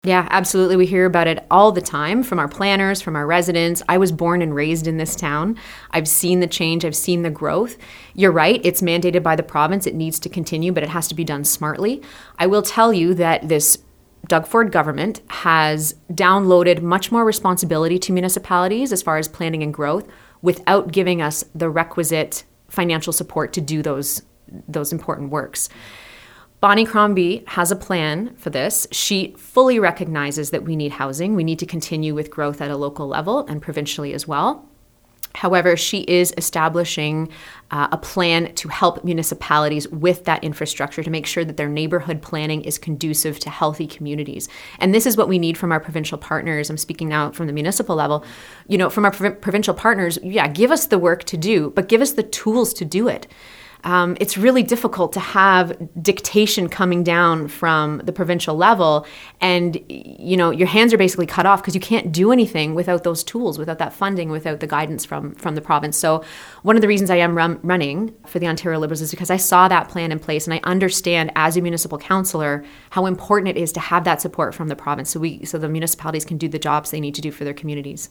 She chose to join us in-person at our Milton studios.
Here’s our interview: